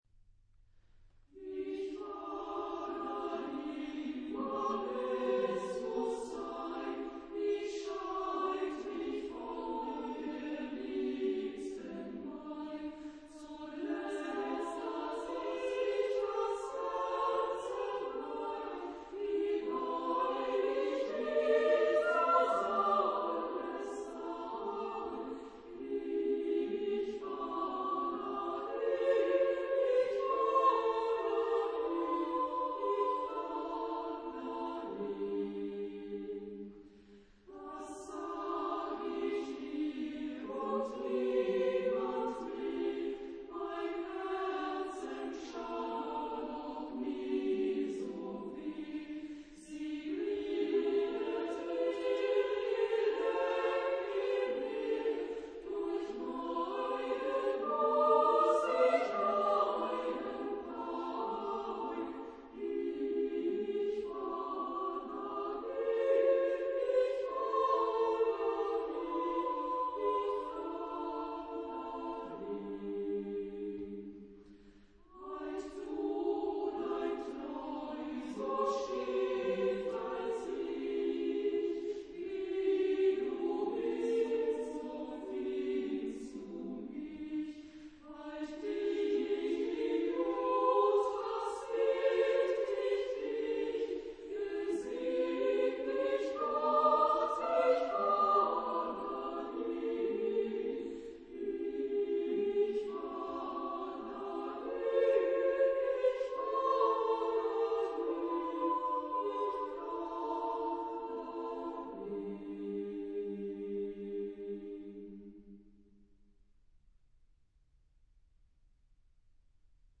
Genre-Stil-Form: weltlich ; Volkstümlich
Chorgattung: SSA  (3 Frauenchor Stimmen )
Tonart(en): G-Dur
Aufnahme Bestellnummer: Internationaler Kammerchor Wettbewerb Marktoberdorf